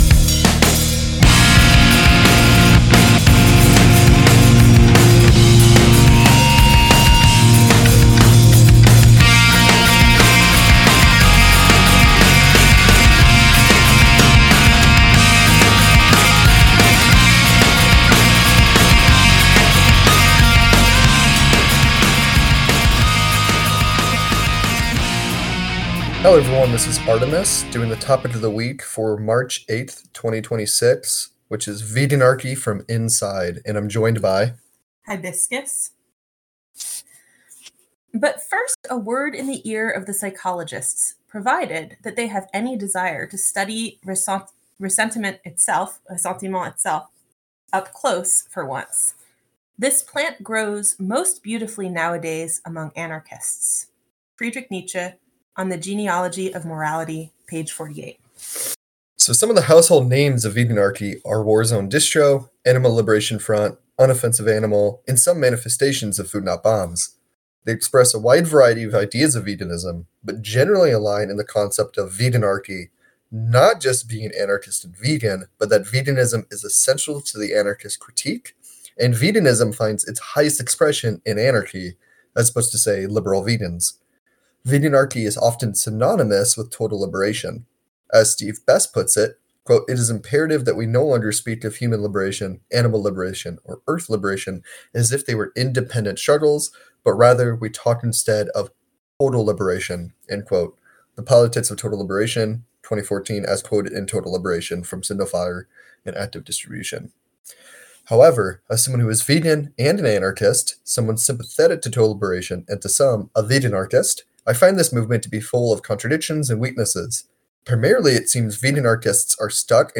Topic of the Week: Veganarchy from Inside a conversation